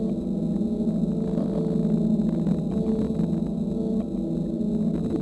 A320_flapmotor.wav